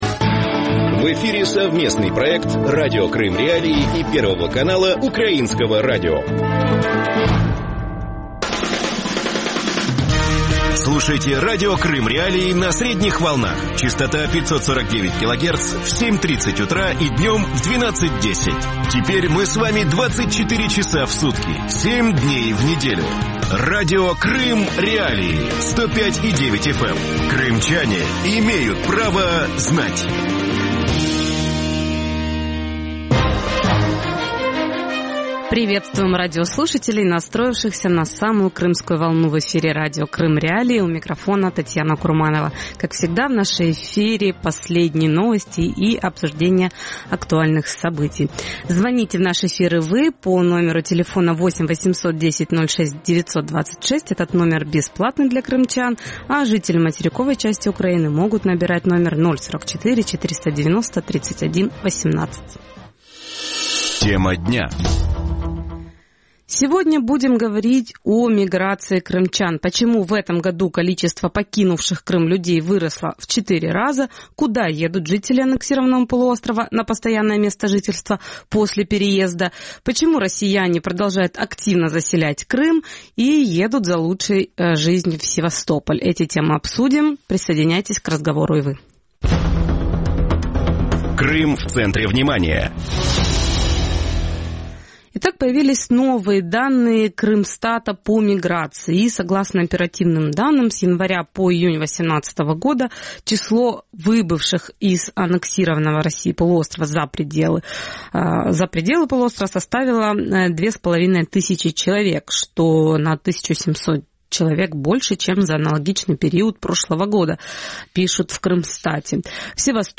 Радио Крым.Реалии вещает 24 часа в сутки на частоте 105.9 FM на северный Крым.